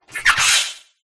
impidimp_ambient.ogg